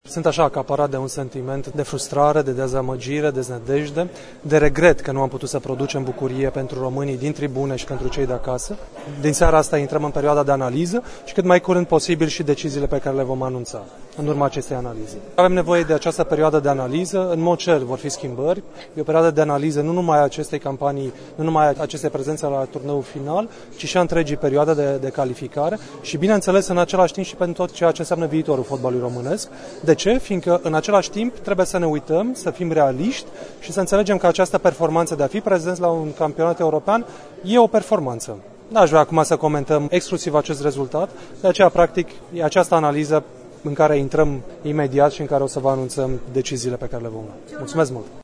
Concluziile meciului de la Lyon le-a tras și președintele FRF, Răzvan Burleanu:
20-iun-9.30-Razvan-Burleanu-presedinte-FRF.mp3